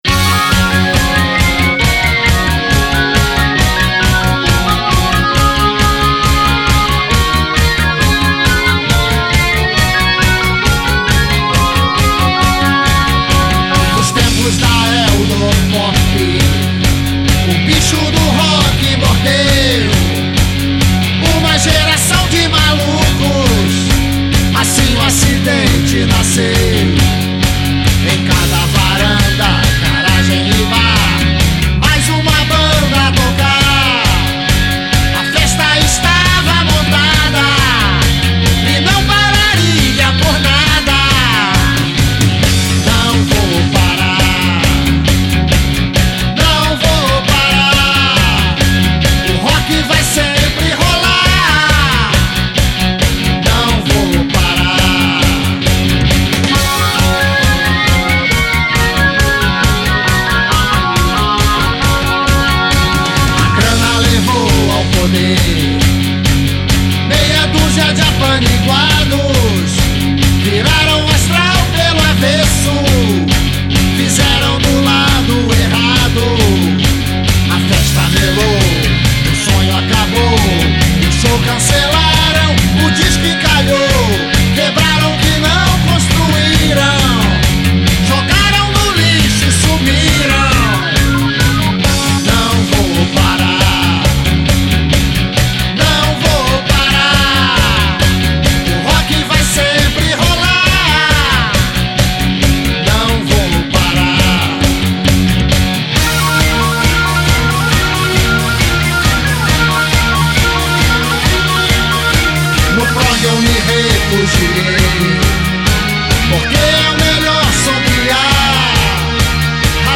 EstiloRock Progressivo